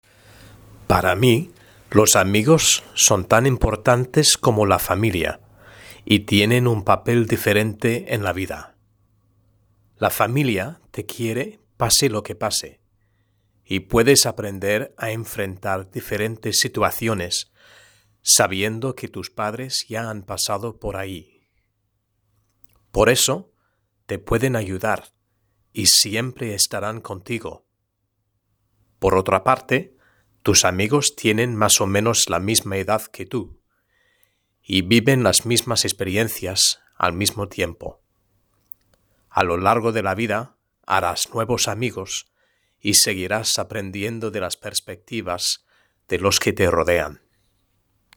Lectura en voz alta: 1.1 La identidad y las relaciones con los otros (H)